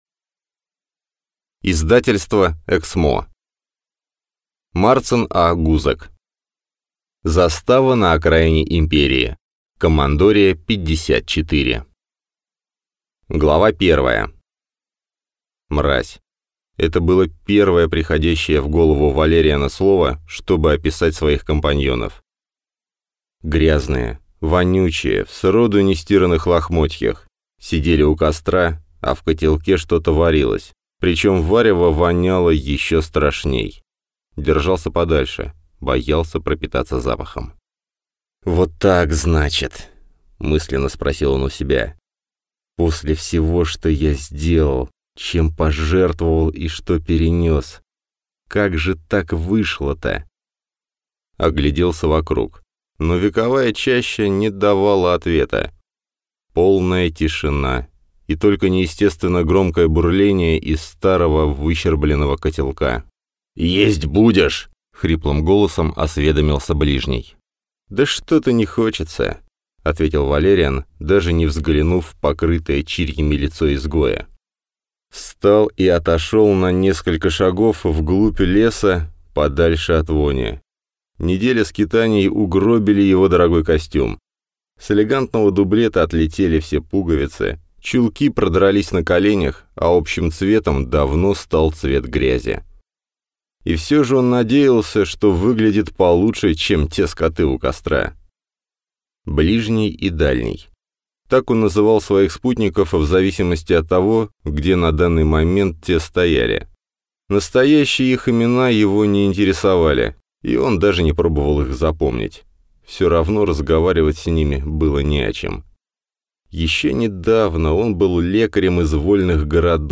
Аудиокнига Застава на окраине Империи. Командория 54 | Библиотека аудиокниг